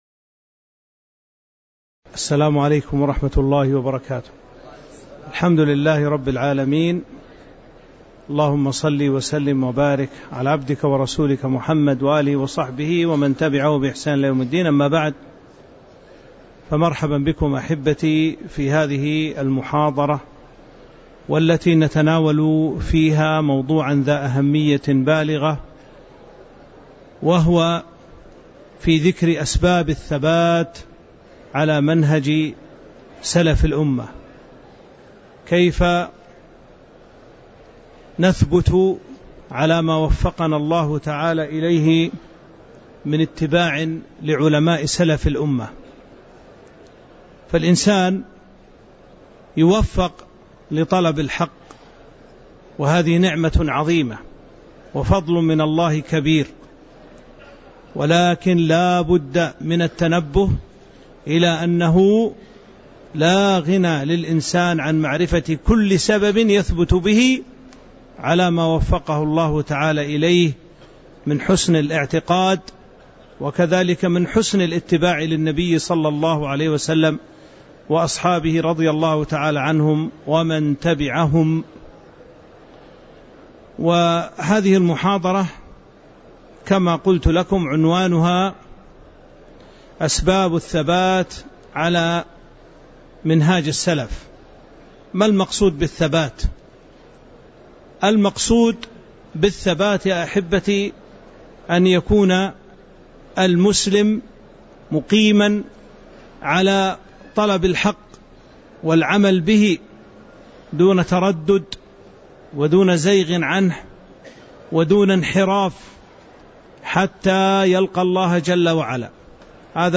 تاريخ النشر ٢٤ ذو الحجة ١٤٤٥ هـ المكان: المسجد النبوي الشيخ